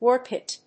アクセントwórk it